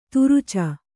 ♪ turuca